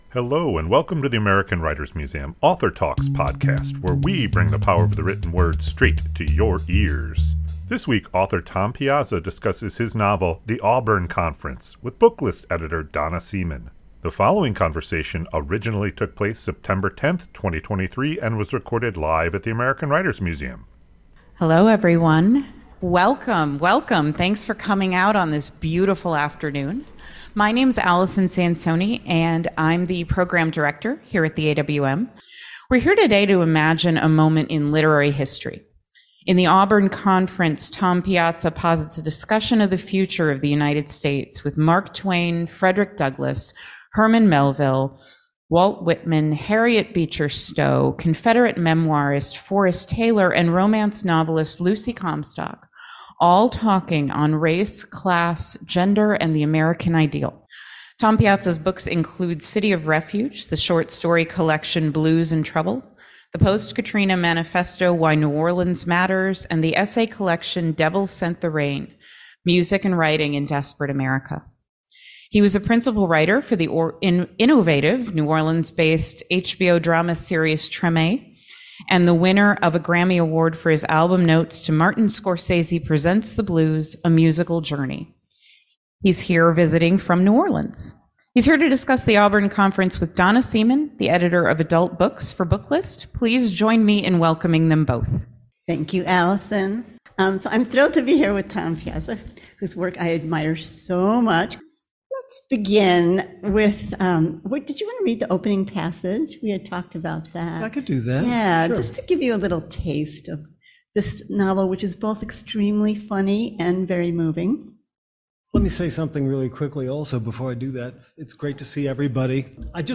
This conversation originally took place September 10, 2023 and was recorded live at the American Writers Museum.